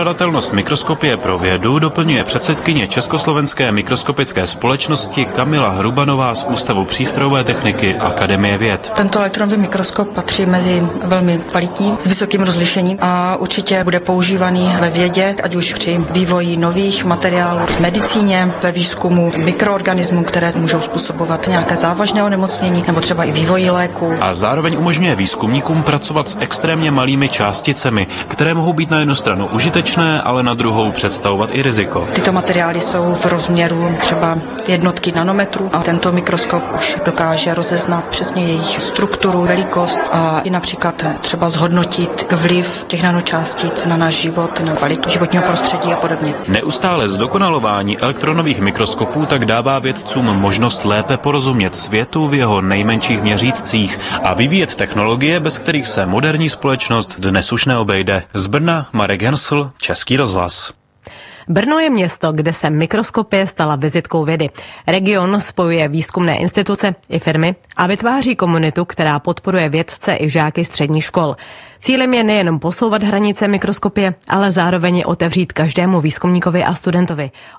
Rozhovor byl natočen u příležitosti představení nové mikroskopické laboratoře ve společnosti AdvaScope s.r.o. a přináší pohled na význam moderní mikroskopie a její roli ve špičkovém výzkumu.